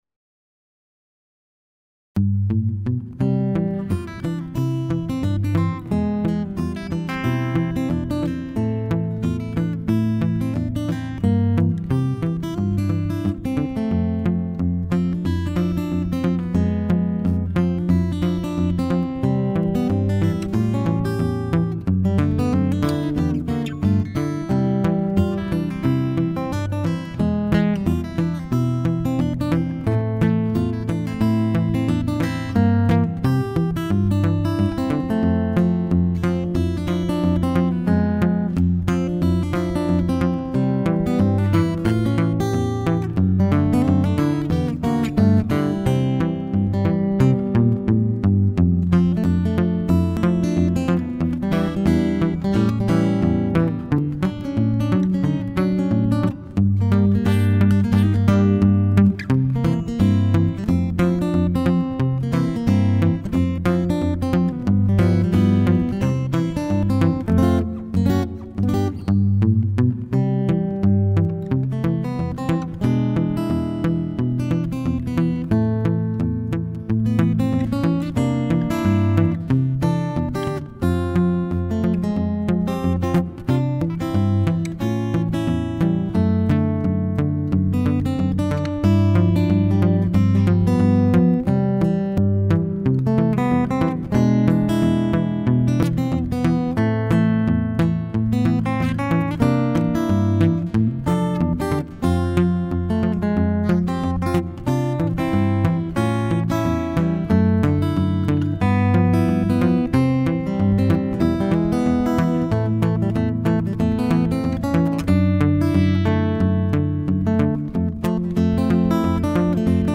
This guy is a very good guitarist and has recorded some songs with this guitar.
The sound of this Adamas is purely amazing!!
The Adamas sounds awful sweet too!